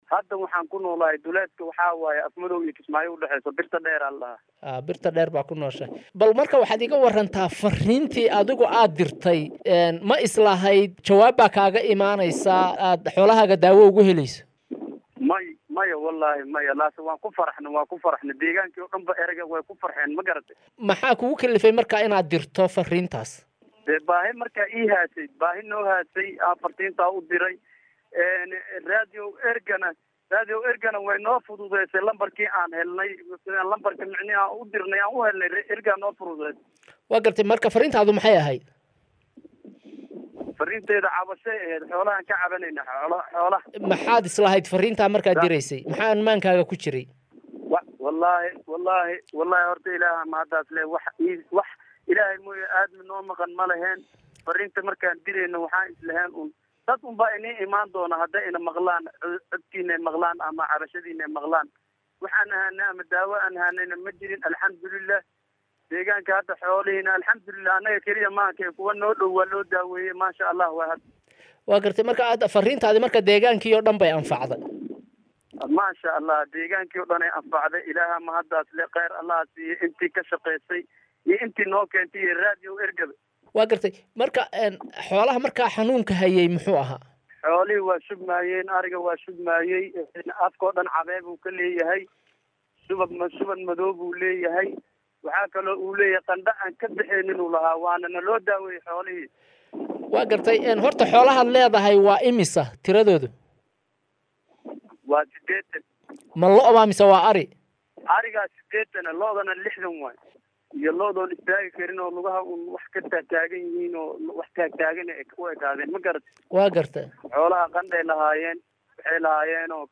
Nin xoolo-dhaqato ah oo wicitaan uu soo wacay Raadiyow Ergo xoolihiisa loogu daweyay